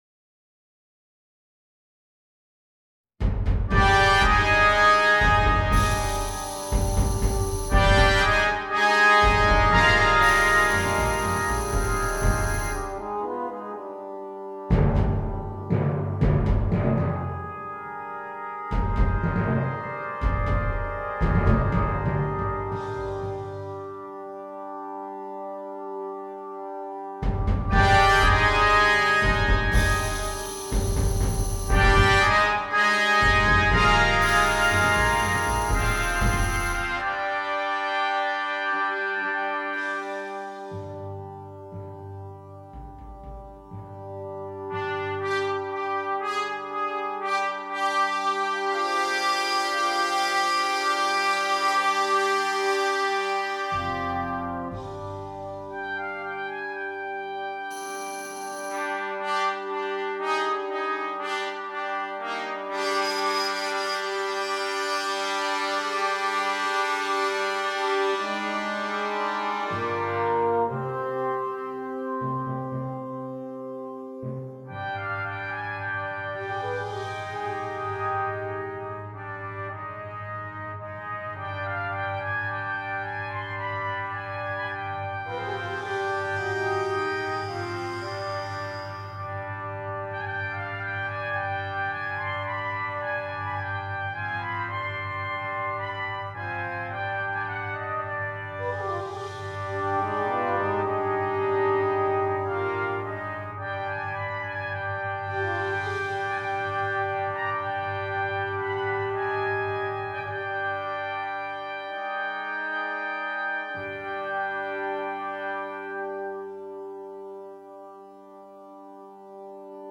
20 Trumpets and Percussion